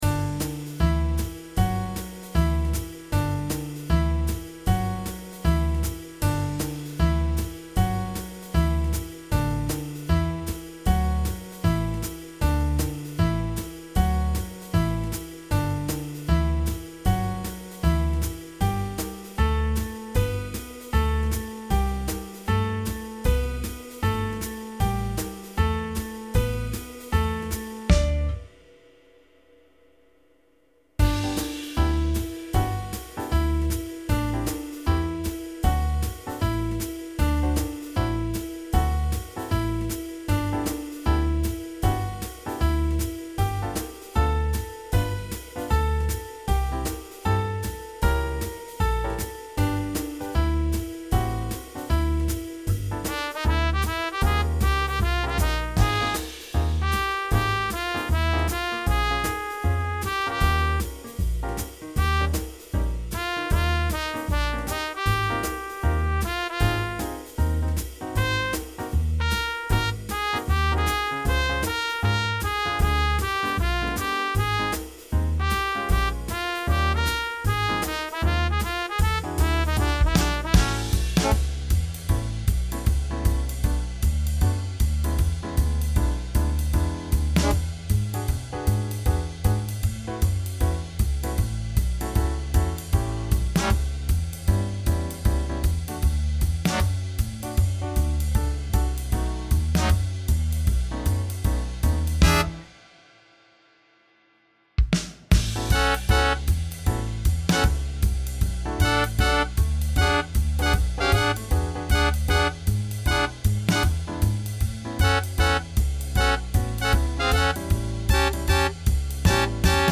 Features trumpet solo